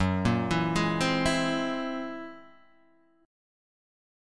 F# 9th Flat 5th